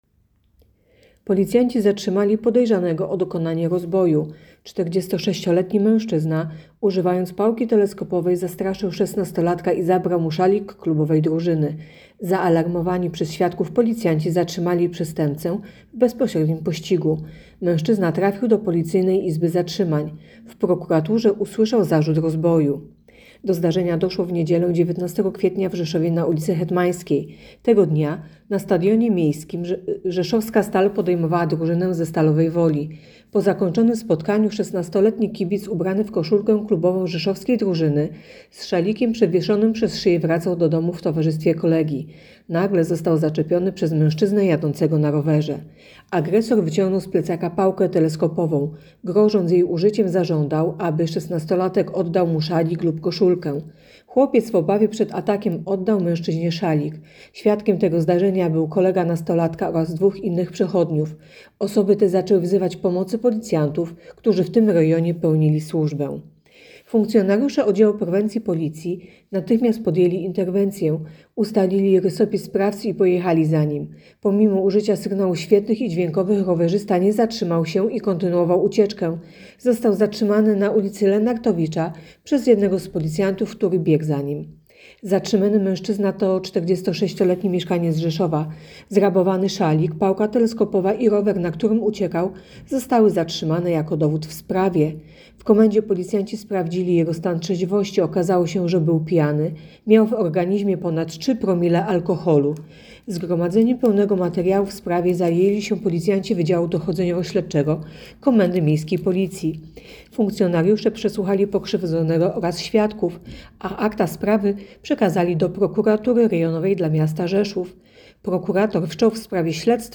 Opis nagrania: Nagranie informacji pt. Policjanci zatrzymali podejrzanego o rozbój na nastolatku.